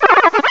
cry_not_turtwig.aif